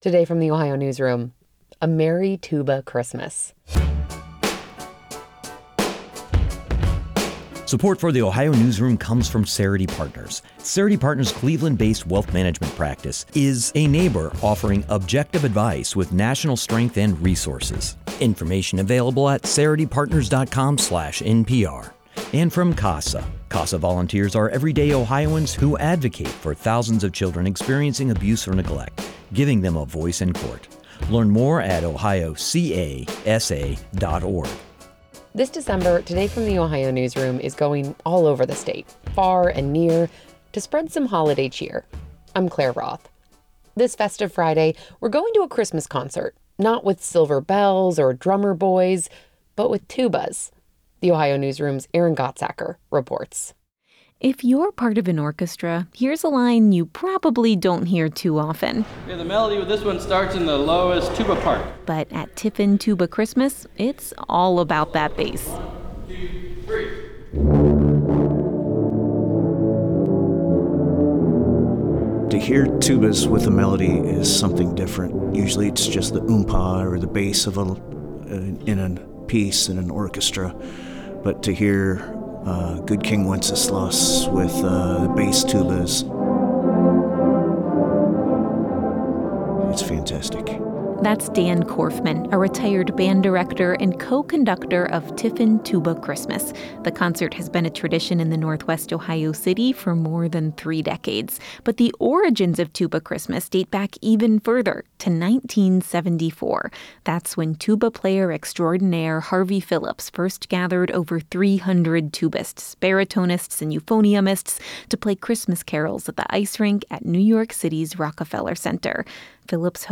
At special holiday concerts across Ohio, tuba, baritone and euphonium players take the center stage.
A chorus of tubas
The chorus of low brass admittedly sounds a little different than a traditional orchestra.
tuba-christmas-web.mp3